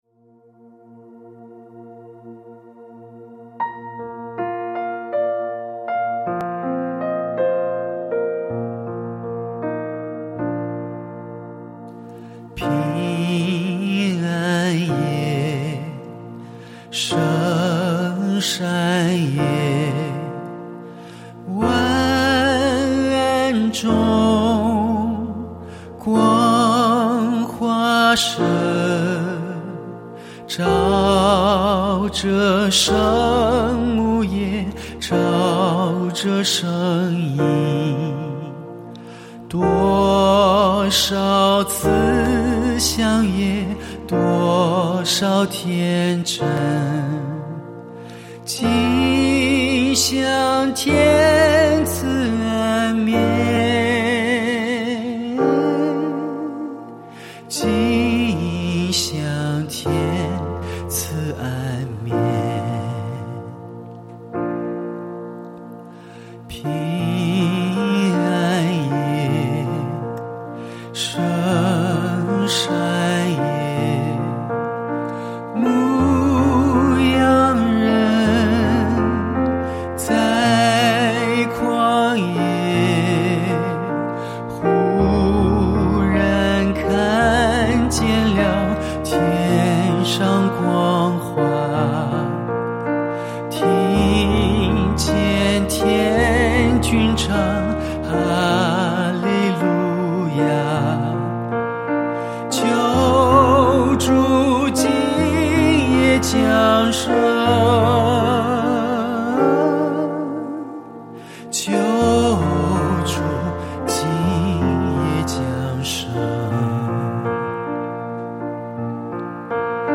轻松学会弹《平安夜》(独奏)简单版 钢琴独奏《平安夜》教学版 技巧贴士： 1、同学们，没有左右手五线谱，这是老师即兴演奏的。